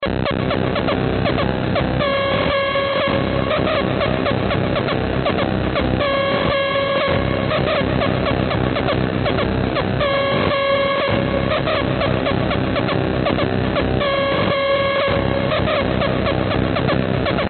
Tag: 环境 噪声 记录 样品